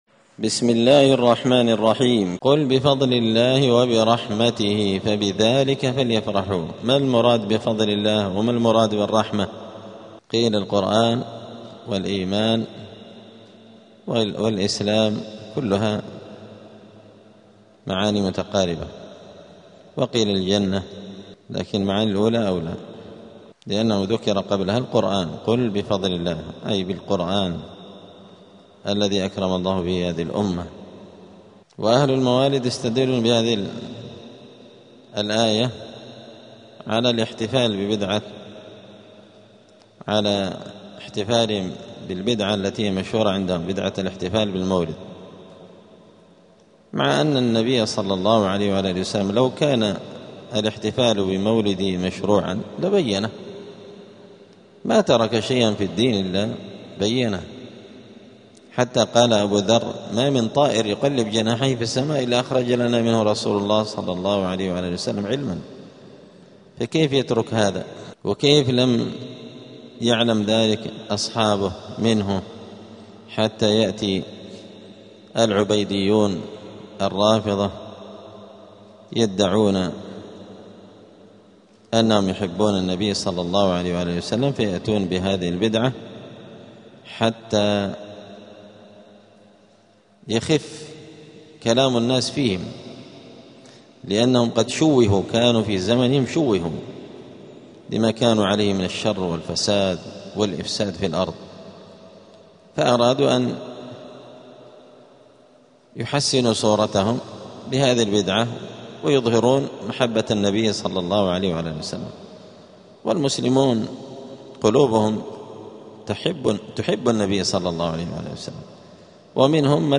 📌الدروس اليومية
دار الحديث السلفية بمسجد الفرقان قشن المهرة اليمن